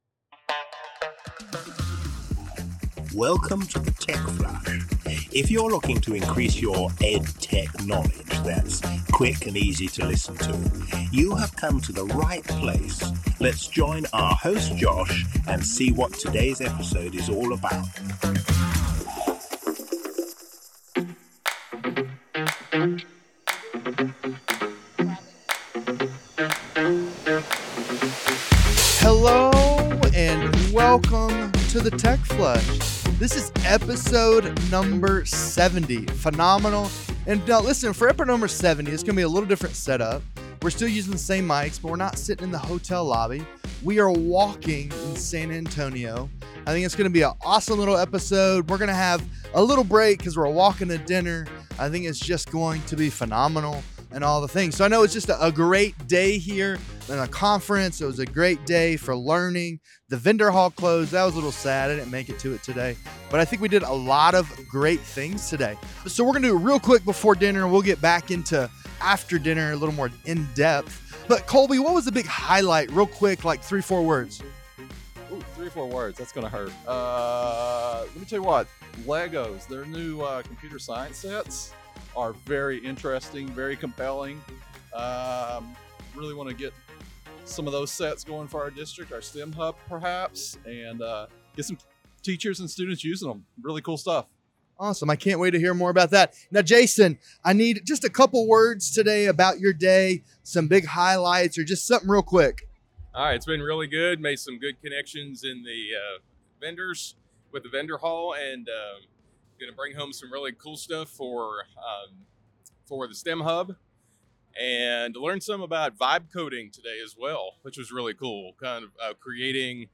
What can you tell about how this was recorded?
This episode is a little different—we recorded the first half while walking 0.2 miles to dinner and finished up back at the hotel lobby.